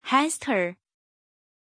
Pronunția numelui Hester
pronunciation-hester-zh.mp3